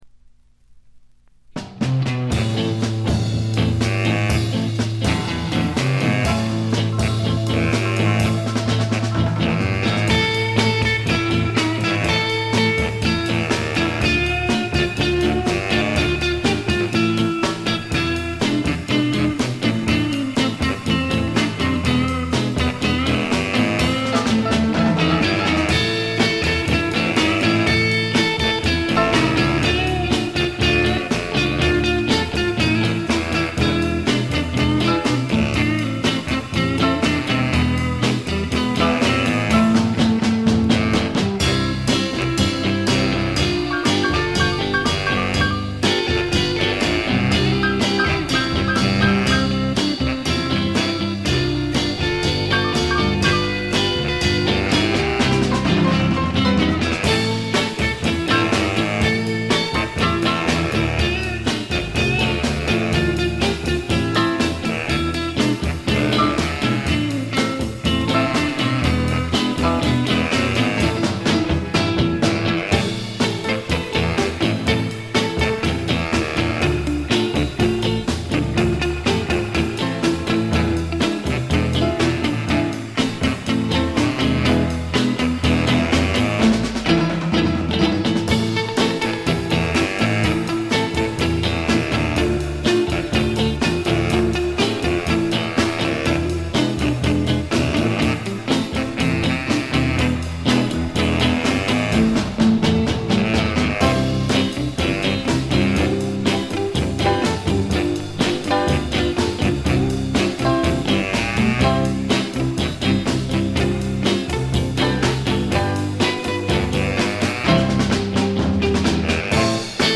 わずかなノイズ感のみ。
ぶりぶりで楽しいインスト集。
試聴曲は現品からの取り込み音源です。